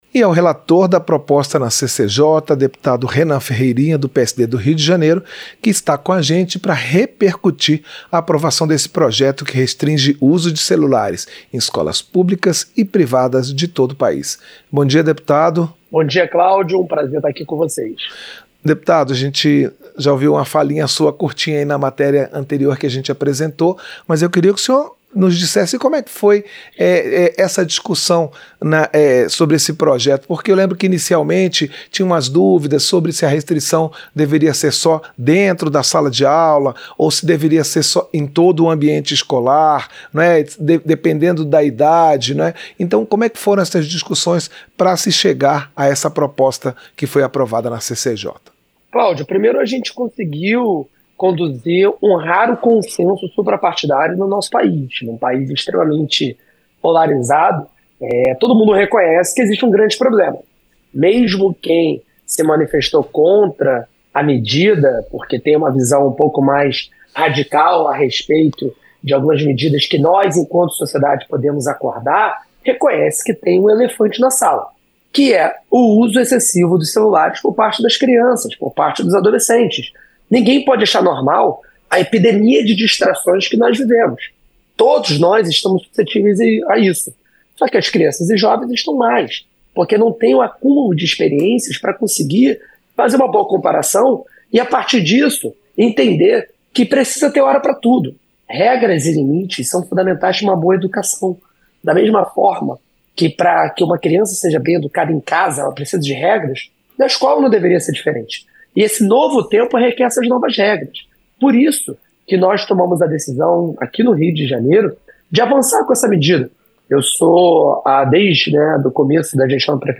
Entrevista - Dep. Renan Ferreirinha (PSD-RJ)